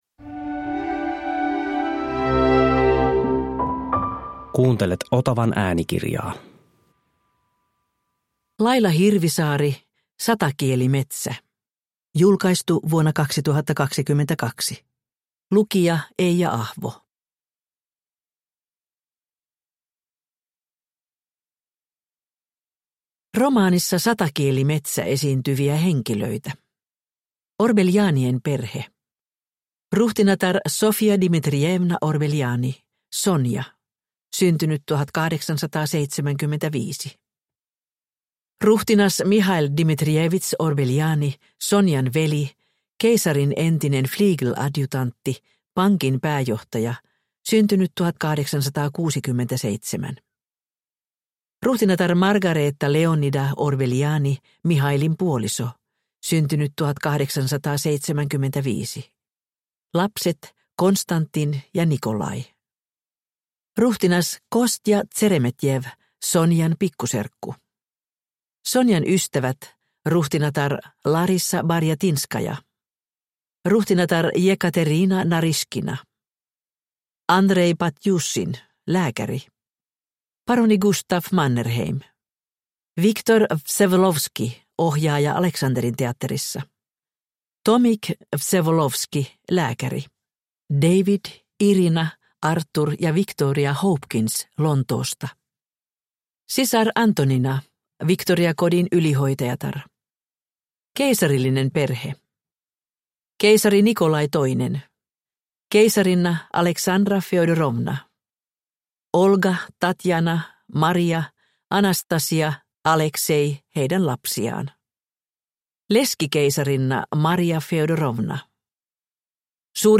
Satakielimetsä – Ljudbok – Laddas ner
Uppläsare: Eija Ahvo